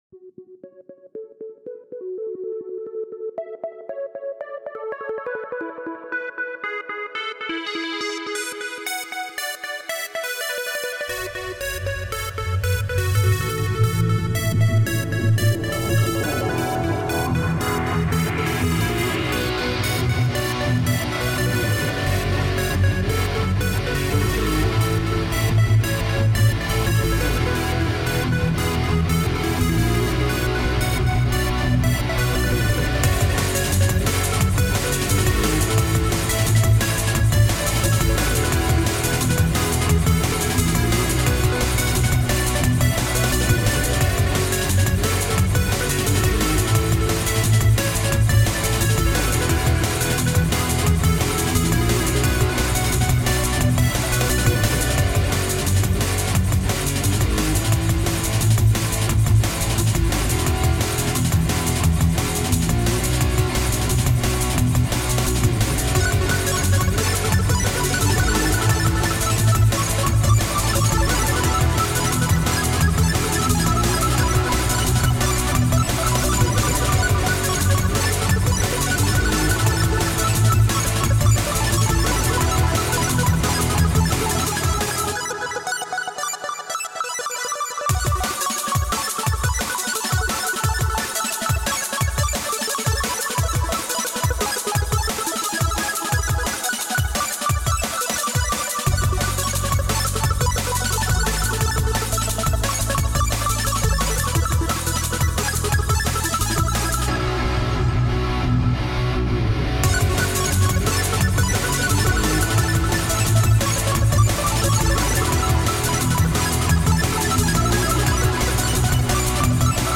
Guitars
Synths, drums, bass